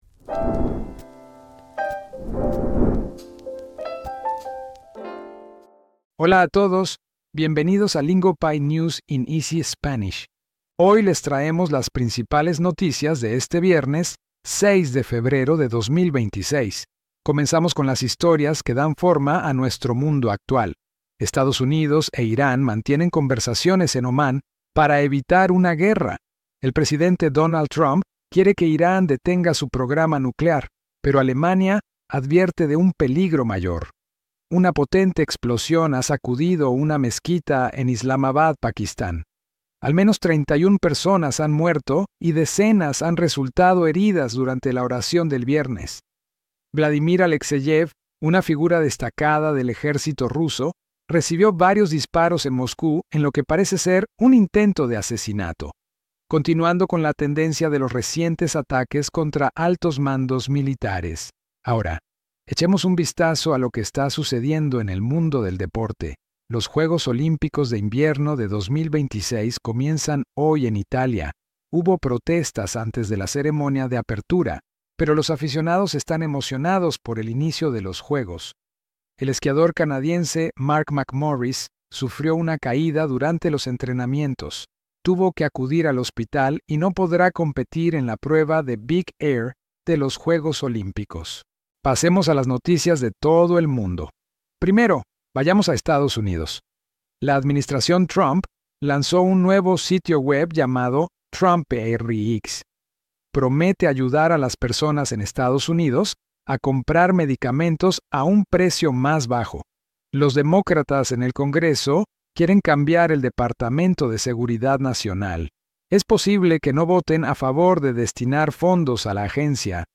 Lingopie's News in Easy Spanish is the slow Spanish news podcast that keeps you in the loop without leaving you lost. Real global headlines, delivered in clear and beginner-friendly Spanish so your listening practice actually goes somewhere.